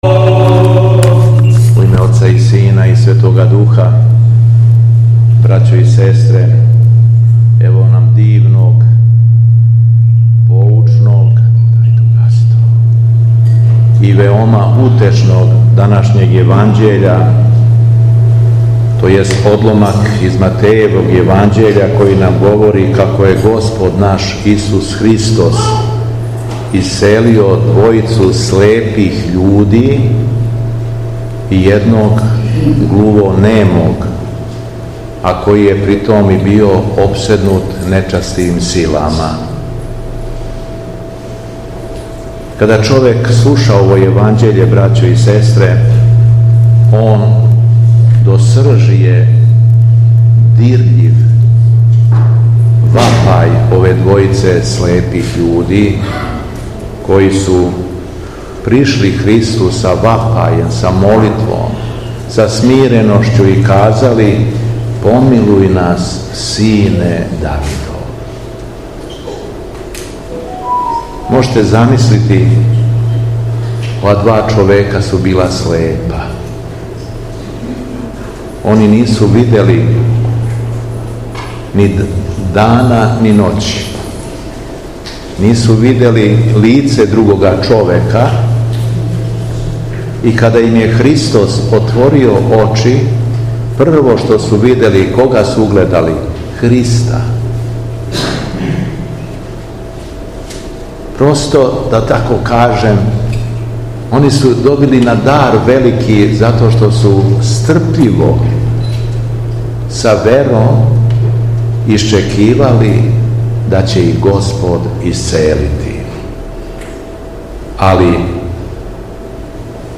Беседа Његовог Високопреосвештенства Митрополита шумадијског г. Јована
После прочитаног јеванђелског зачала, Митрополит се обратио поучном беседом сабраном народу: